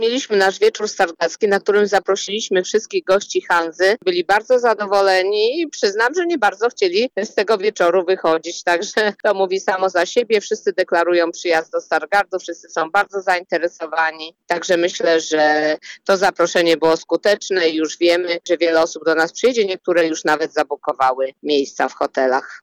Podsumowuje je Ewa Sowa, zastępczyni prezydenta miasta: – Podczas zjazdu zorganizowaliśmy wieczór stargardzki, na który zaprosiliśmy wszystkich uczestników Hanzy.